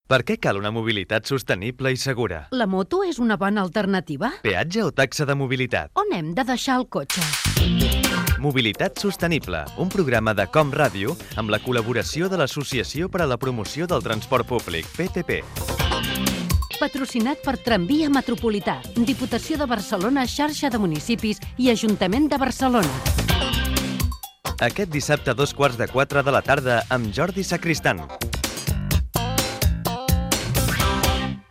Promoció del programa
Divulgació